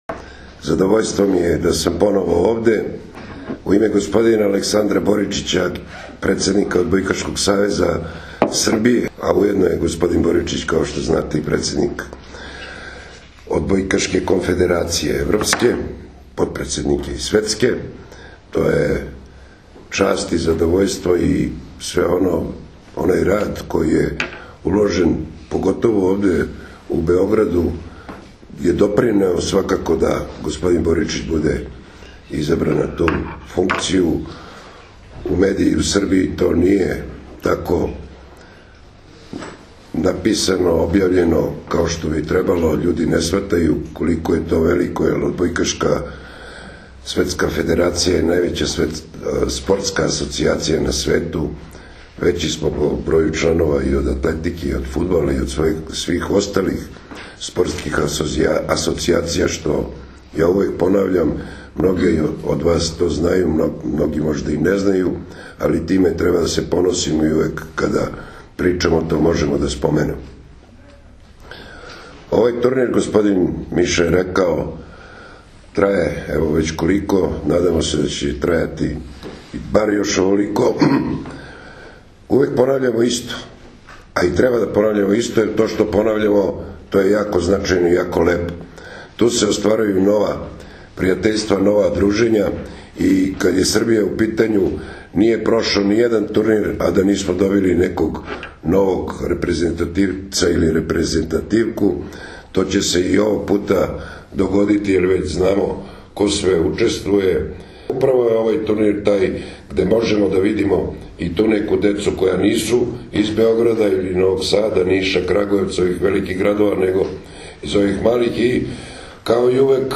U beogradskom hotelu “Belgrade City” danas je održana konferencija za novinare povodom 51. Međunarodnog “Majskog turnira” 2016, koji će se odigrati od 30. aprila – 2. maja.
IZJAVA